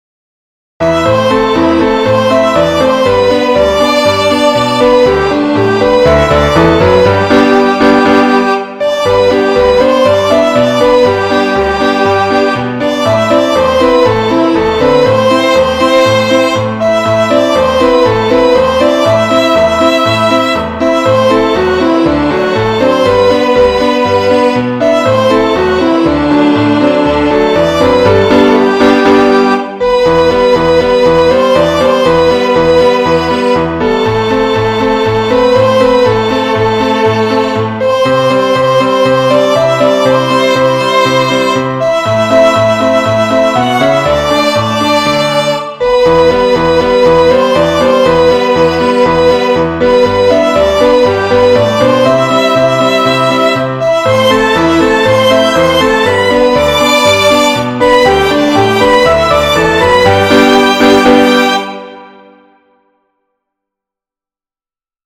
3  MARCHAS POPULARES para 2026